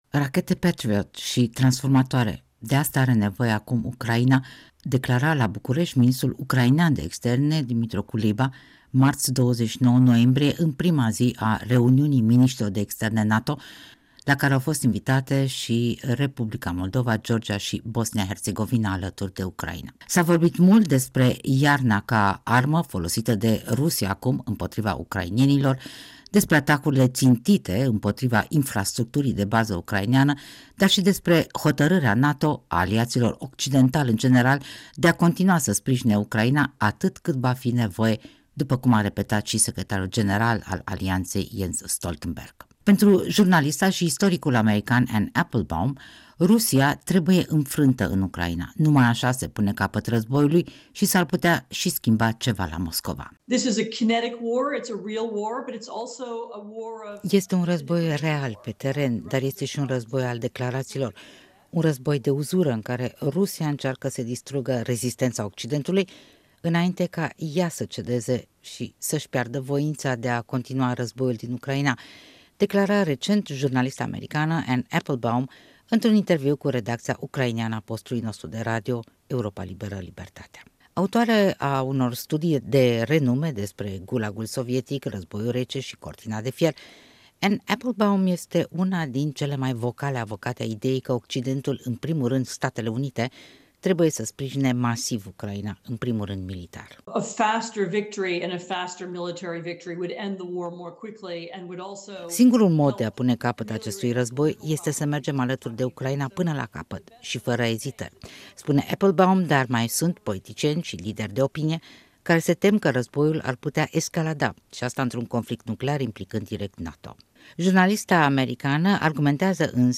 „Este un război real, pe teren, dar este și un război al declarațiilor”, un război de uzură, în care Rusia încearcă să distrugă „rezistența” Occidentului, înainte ca ea să „cedeze”, să-și piardă „voința” de a continua războiul din Ucraina”, avertiza recent jurnalista americană Anne Applebaum într-un interviu cu redacția ucraineană a postului nostru de radio, Europa Liberă/Libertatea (RFE/RL).